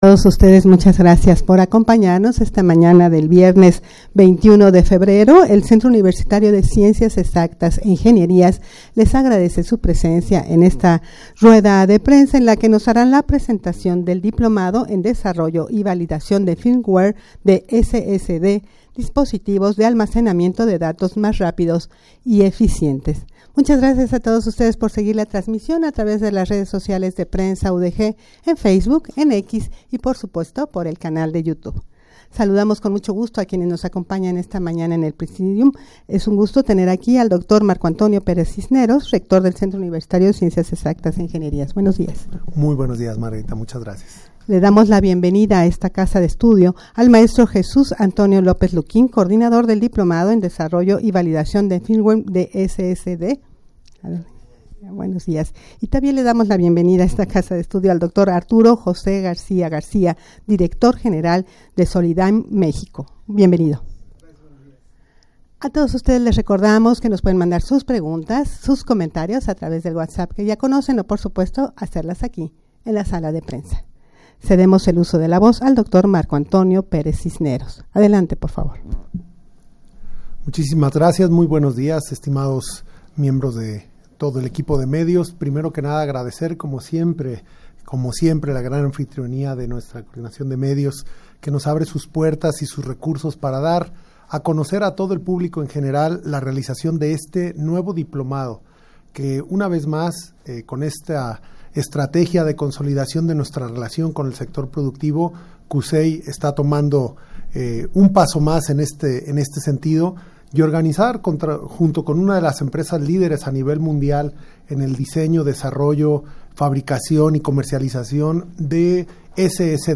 Audio de la Rueda de Prensa
rueda-de-prensa-presentacion-del-diplomado-en-desarrollo-y-validacion-de-firmware-de-ssds.mp3